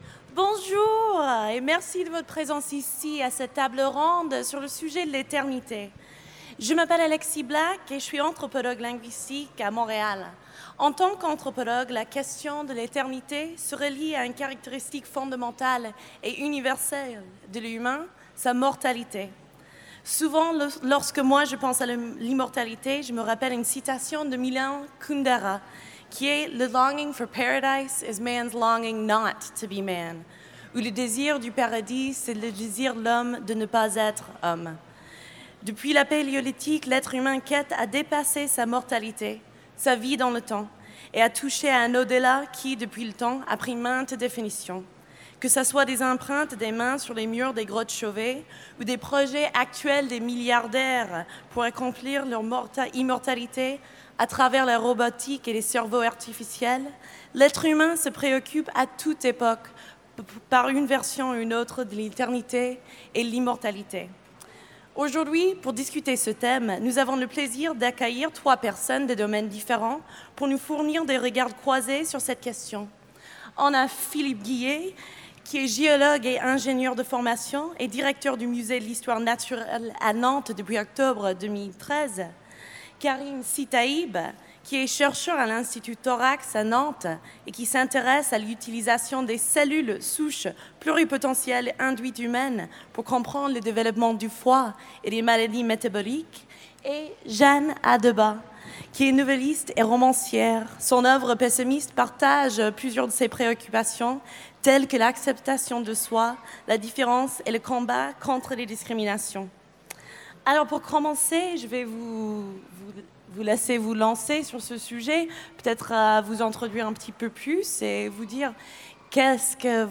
Utopiales 2017 : Conférence Éternité